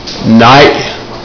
neih.wav